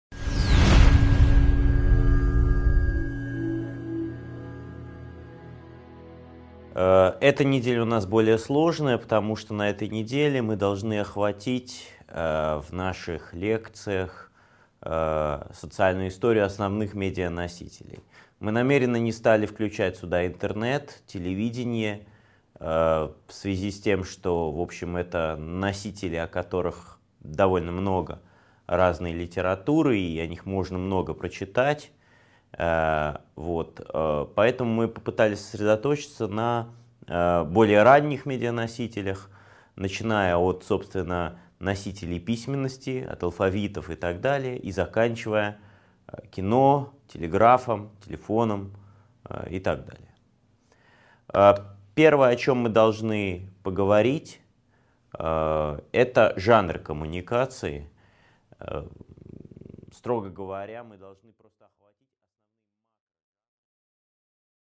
Аудиокнига 4.1 Жанры коммуникации | Библиотека аудиокниг